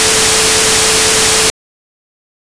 A continuación se puede acceder a cinco audiciones que harán comprender mejor la audición binaural humana, y para las cuales se hace necesario la utilización de auriculares.
Audición 1: Tono de referencia (onda sinusoidal) de 440 Hz. para futuras comparaciones.
Audición 5: Tono de referencia más ruido en el auricular izquierdo y tono de referencia (desfasado) más ruido en el derecho.